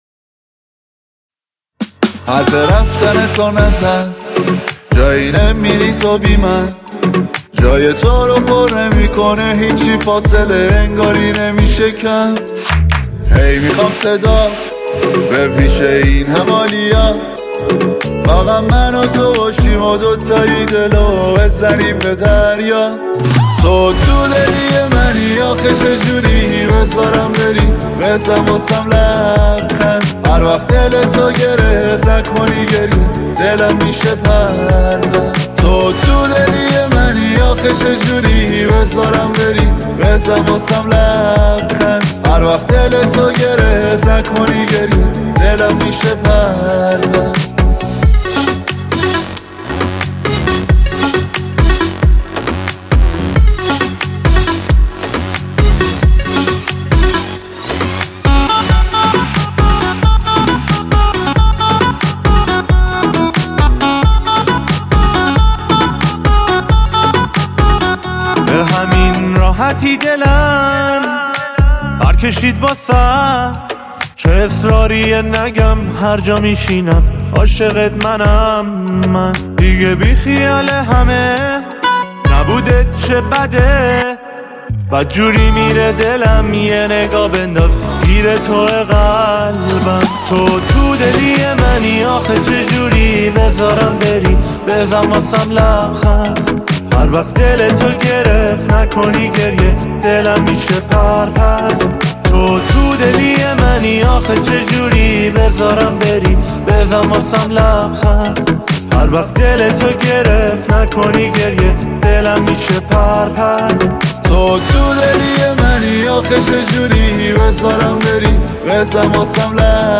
دانلود آهنگ غمگین